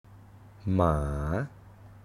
Tone: starts low, rises like a question
ToneMidLowFallingHighRising
Phoneticmaamàamâamáamǎa